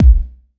• Hot Kick Drum Single Shot B Key 512.wav
Royality free bass drum sample tuned to the B note. Loudest frequency: 111Hz
hot-kick-drum-single-shot-b-key-512-lR9.wav